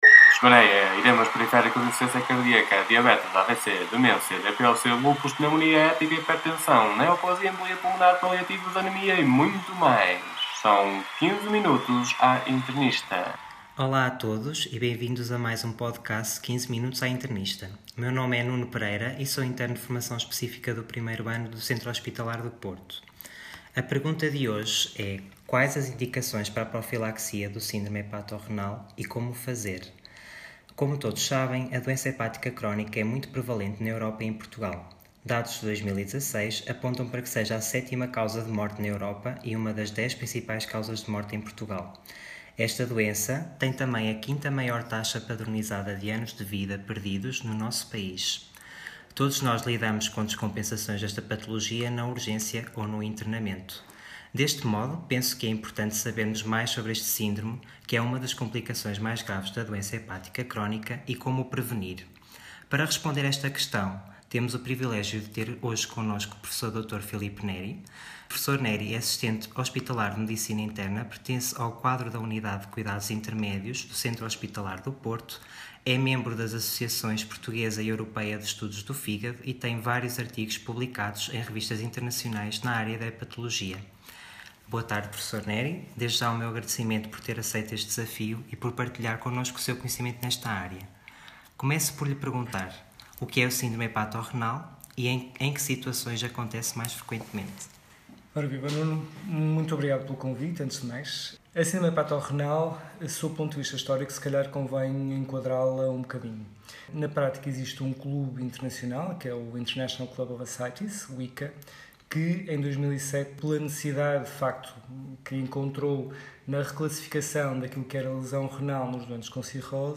à conversa